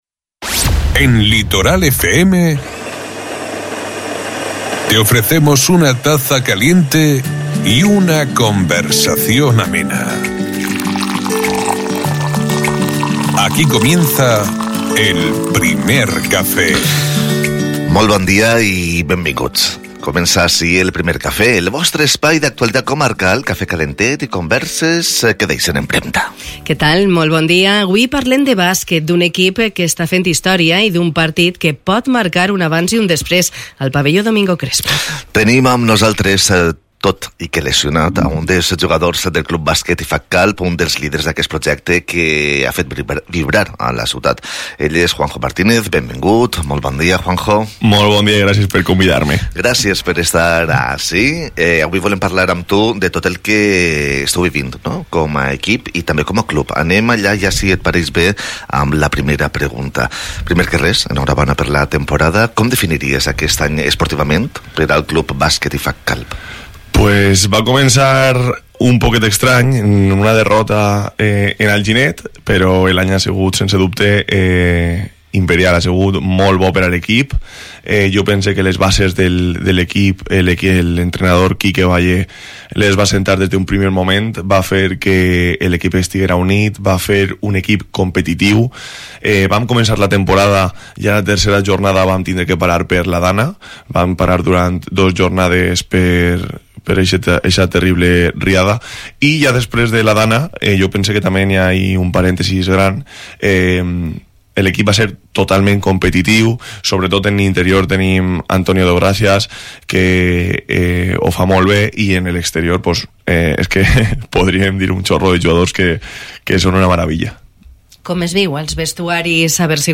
Nuestro invitado también ha destacado el papel fundamental que desempeñan los colaboradores.